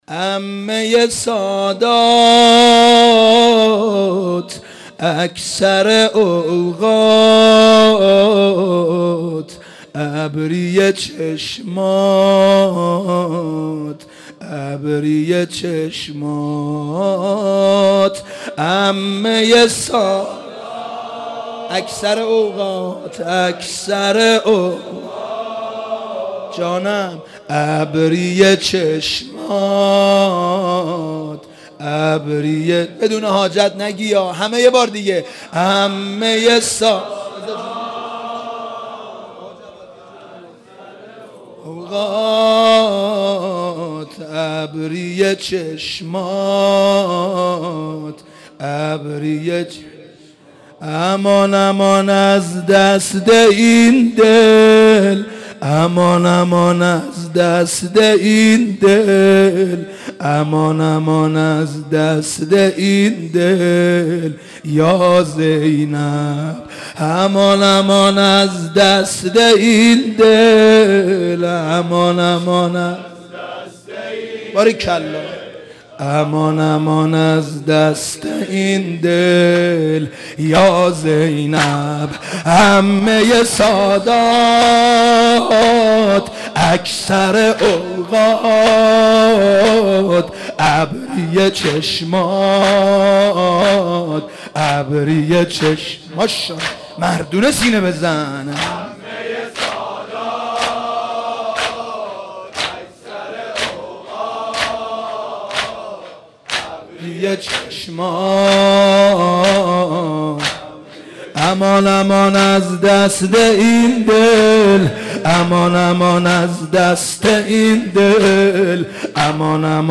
مداح
قالب : زمینه